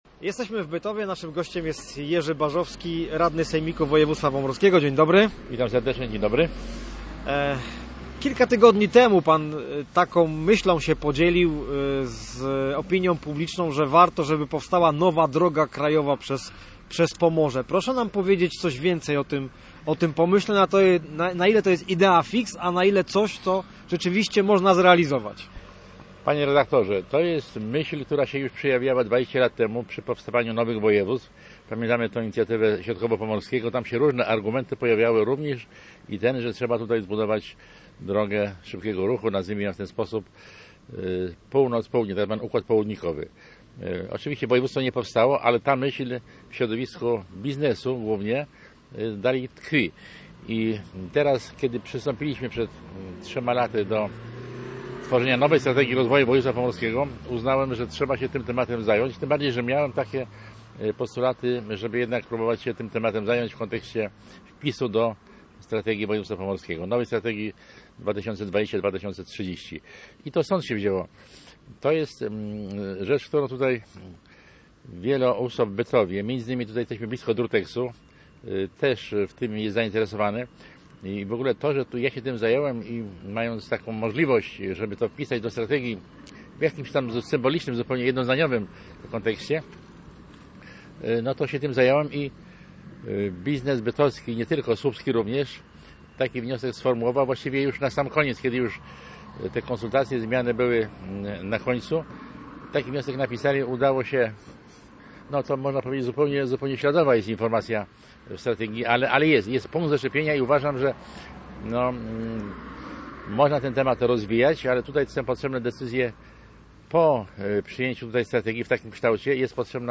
Posłuchaj rozmowy z Jerzym Barzowskim: